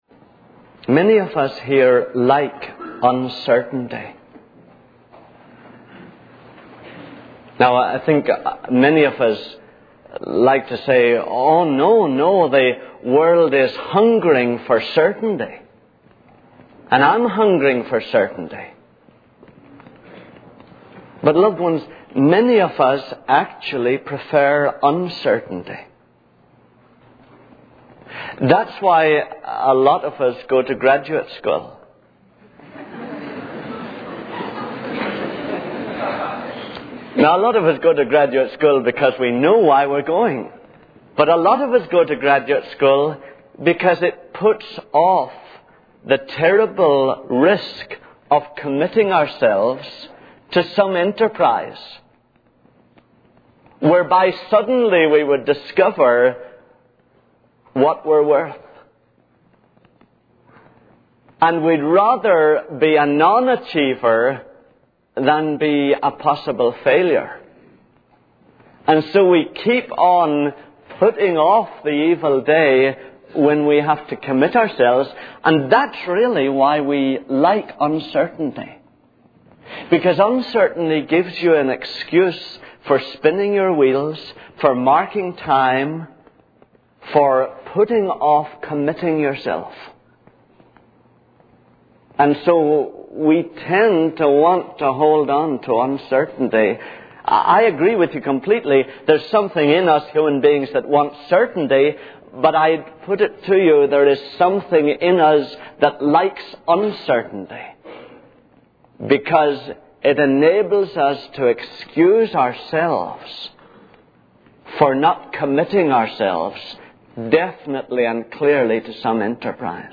In this sermon, the speaker emphasizes the transformative power of Jesus in one's life. He describes how even a dying man can experience a physical and spiritual transformation when they believe in Jesus.